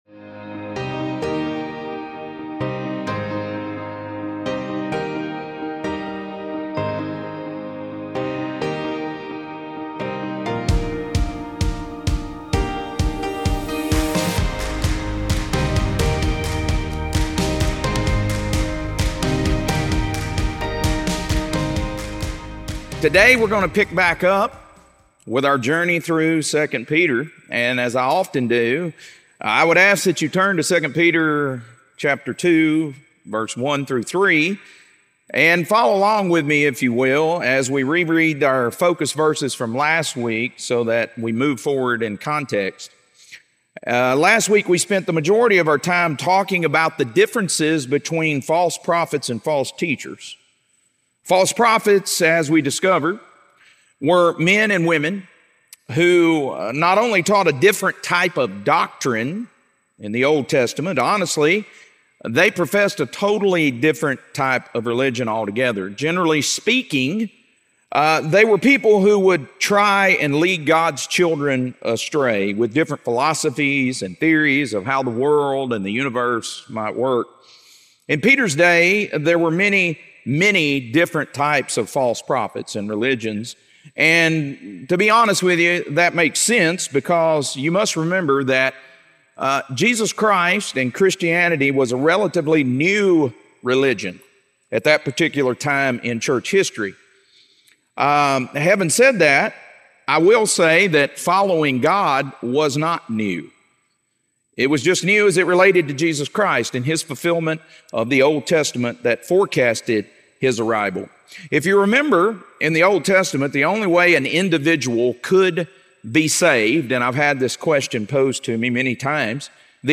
2 Peter - Lesson 2B | Verse By Verse Ministry International